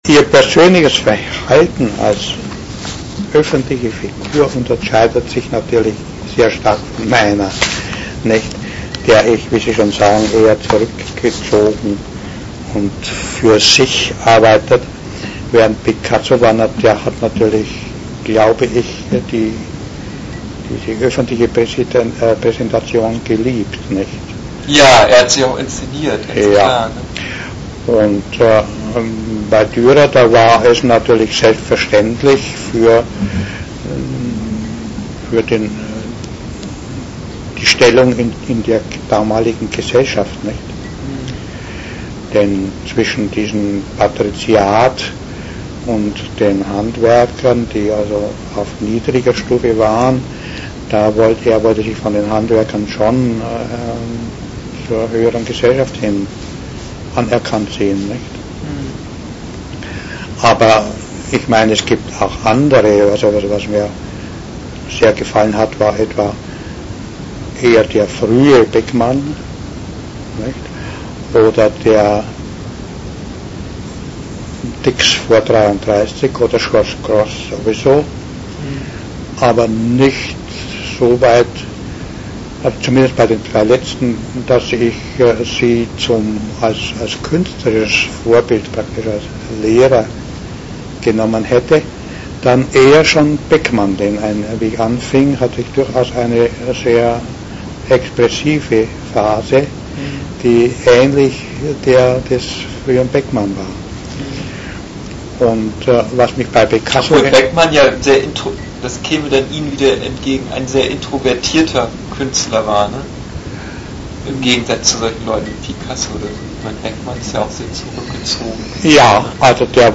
im Gespräch mit